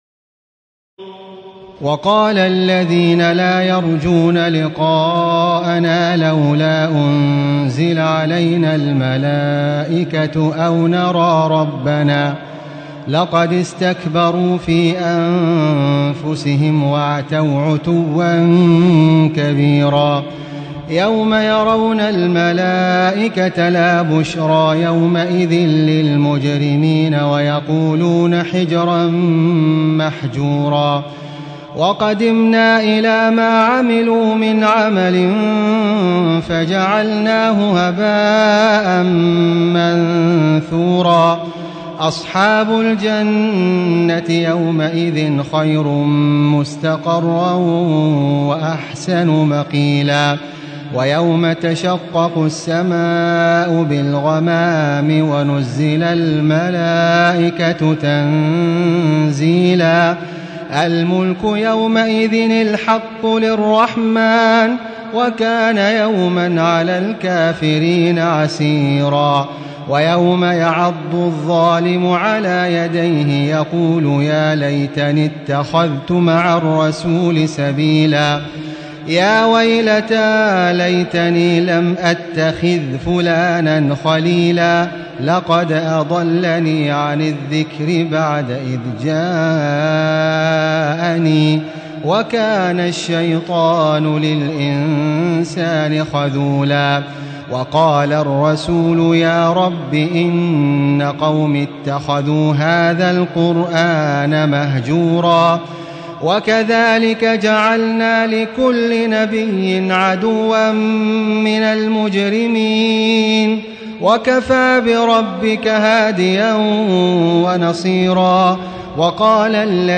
تراويح الليلة الثامنة عشر رمضان 1437هـ من سورتي الفرقان (21-77) و الشعراء (1-104) Taraweeh 18 st night Ramadan 1437H from Surah Al-Furqaan and Ash-Shu'araa > تراويح الحرم المكي عام 1437 🕋 > التراويح - تلاوات الحرمين